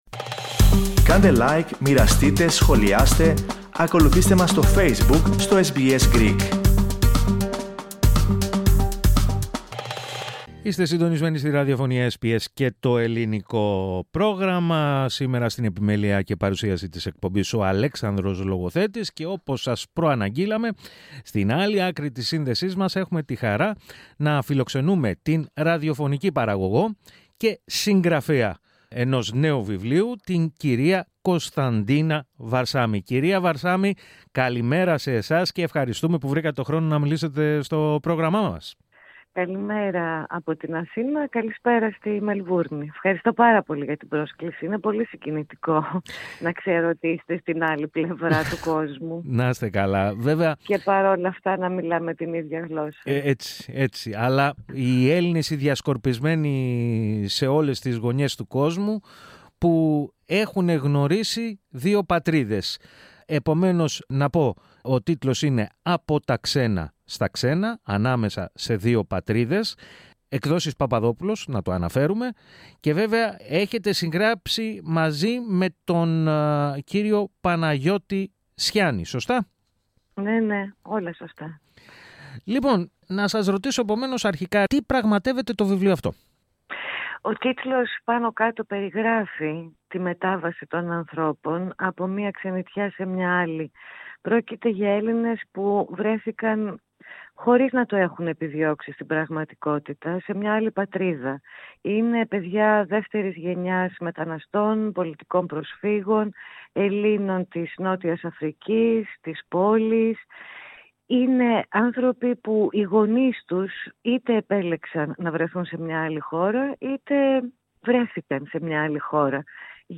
Ακούστε, ολόκληρη τη συνέντευξη, πατώντας PLAY, στην αρχή της σελίδας.